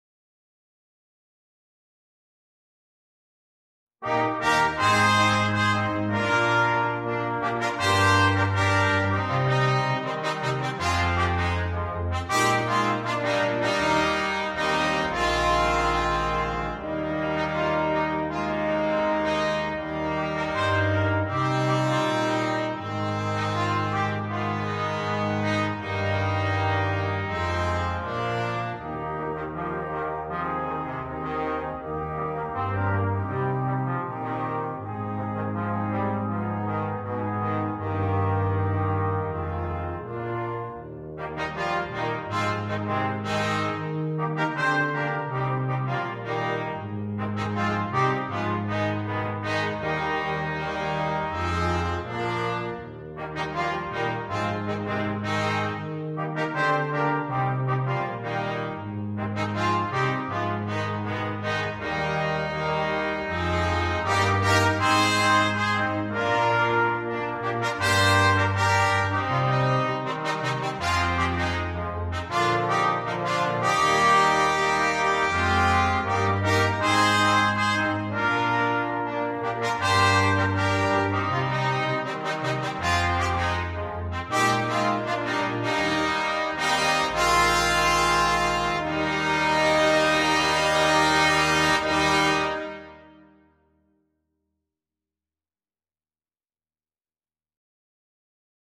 Ноты для брасс-квинтета
Trumpet in B 1, Trumpet in B 2, Horn in F, Trombone, Tuba.